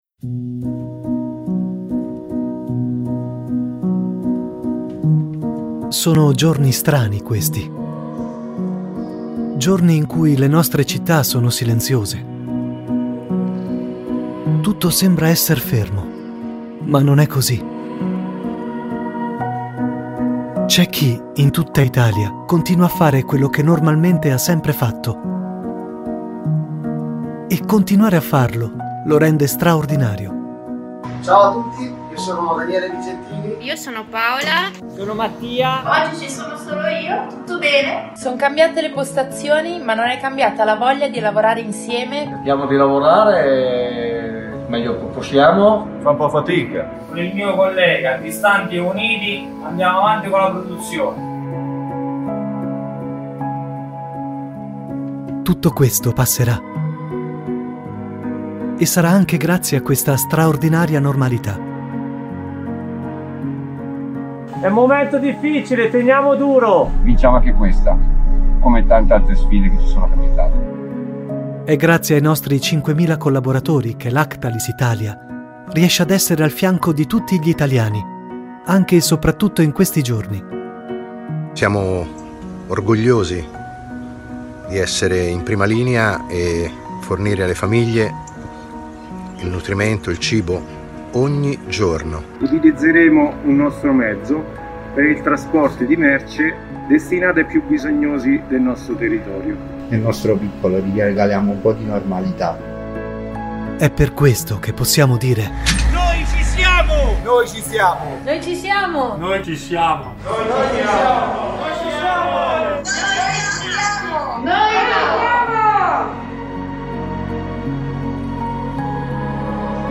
Male 30s , 40s , 50s American English (Native) , Flemish (Native) Approachable , Assured , Authoritative , Bright , Character , Confident , Conversational , Cool , Corporate , Deep , Energetic , Engaging , Friendly , Funny , Gravitas , Natural , Posh , Reassuring , Sarcastic , Smooth , Soft , Upbeat , Versatile , Wacky , Warm , Witty